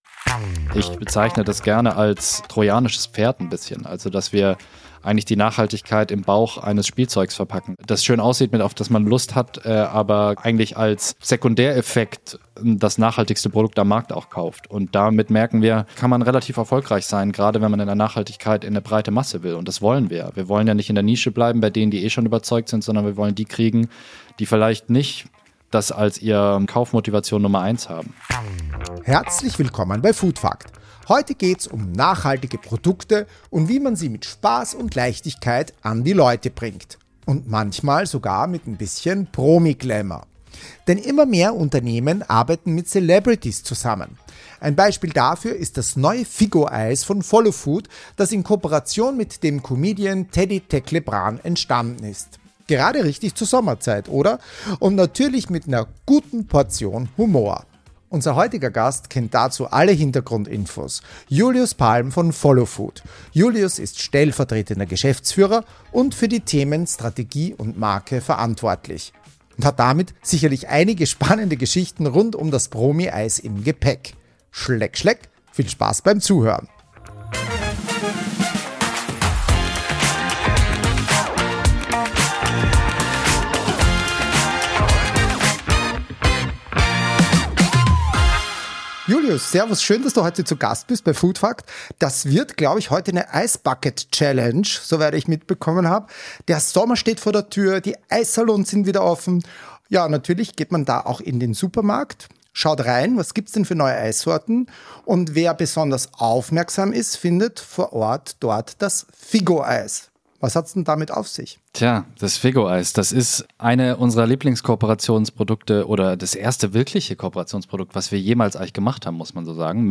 Gemeinsam sprechen die beiden über die Herausforderungen und Chancen nachhaltiger Produktentwicklung im Food-Bereich, die Bedeutung von Innovationen im Convenience- und Snacksegment sowie die kreative Nutzung von Kooperationen mit Celebrities, um Nachhaltigkeit einer breiteren Masse zu vermitteln.